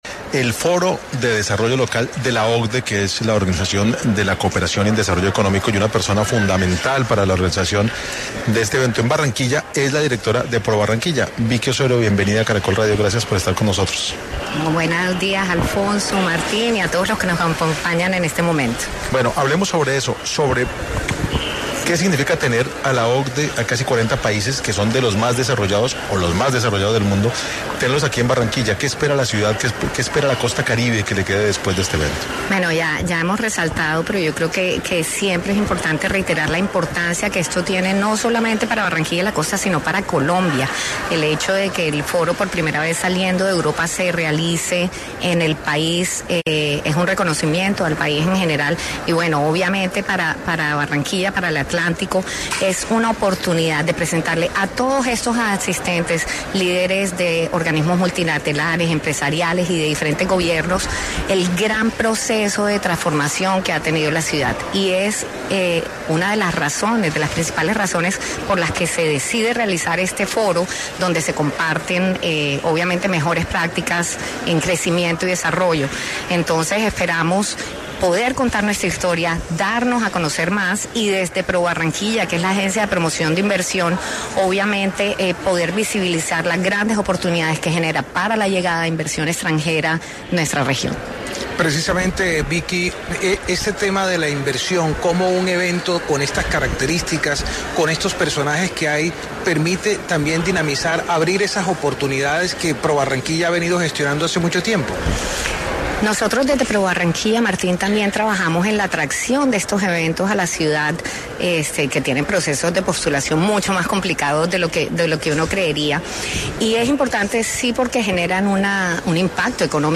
En entrevista para 6AM de Caracol Radio